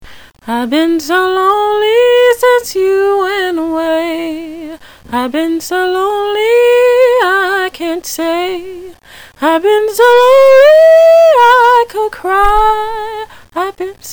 I hope that you enjoy the music here all of which is sang without background music mostly intended for those that have insisted that I create this page so that they can hear me sing a bit more.